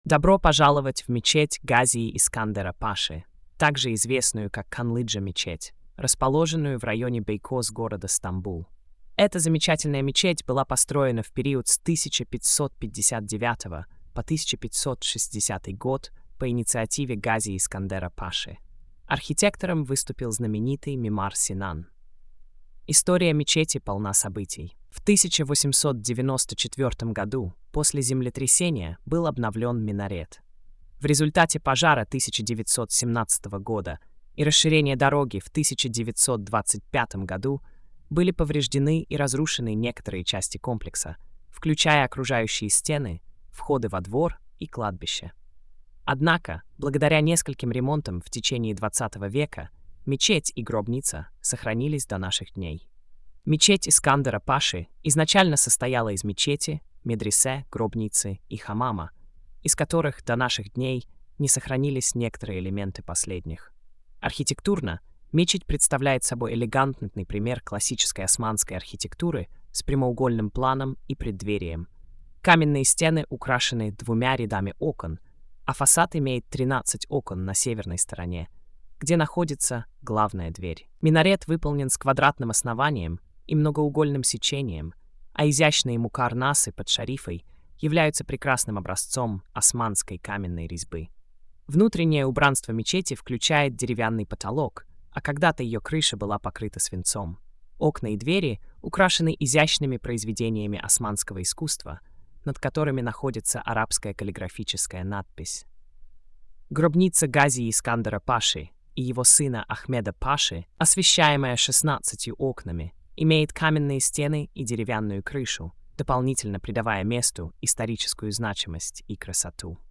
Аудиоповествование